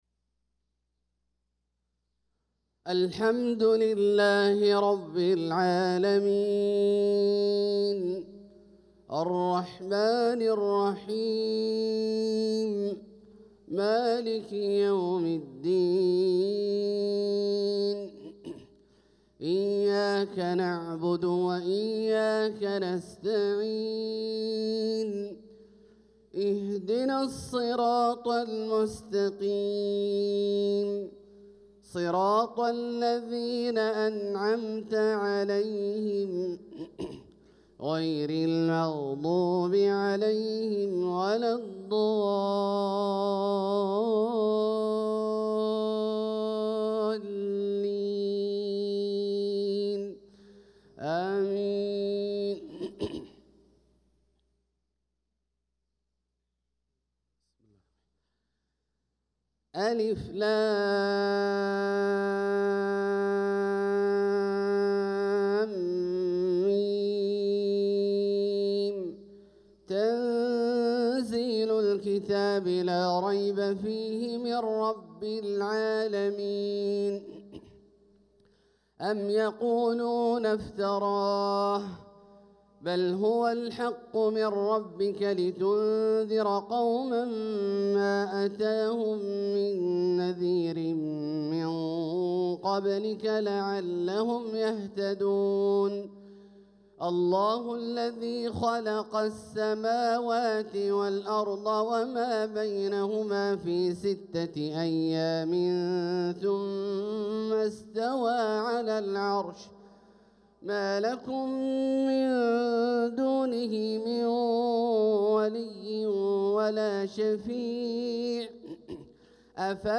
صلاة الفجر للقارئ عبدالله الجهني 24 ربيع الأول 1446 هـ
تِلَاوَات الْحَرَمَيْن .